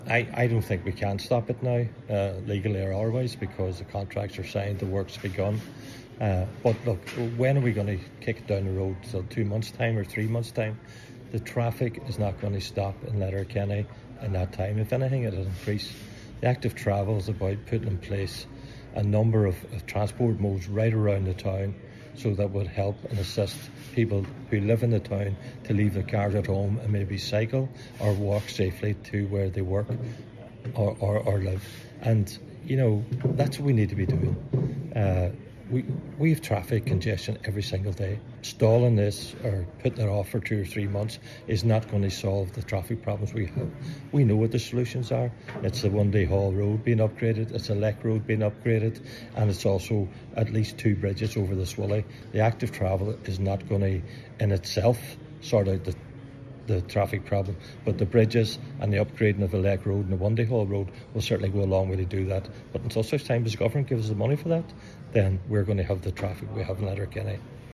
He says until additional Government funding is forthcoming, traffic congestion issues in the town will not be solved: